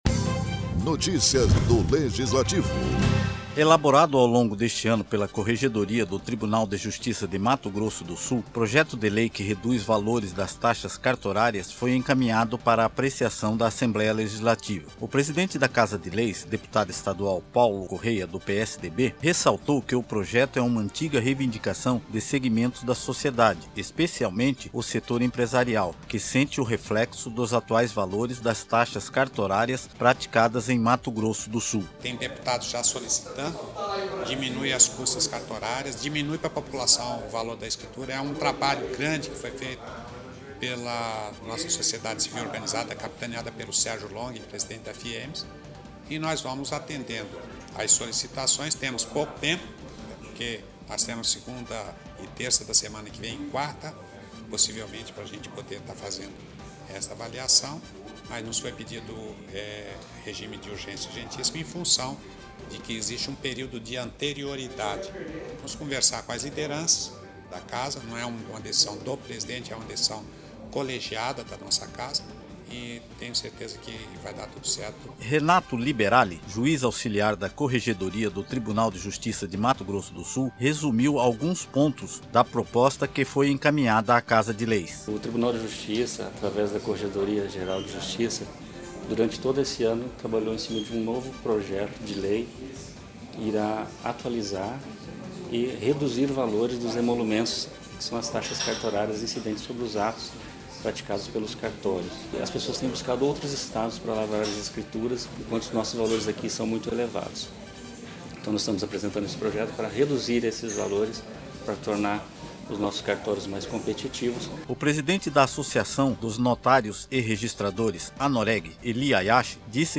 O presidente do Parlamento,  deputado Paulo Corrêa, do PSDB falou que a proposta deverá tramitar em regime de urgência para passe a vigorar a partir de  2020.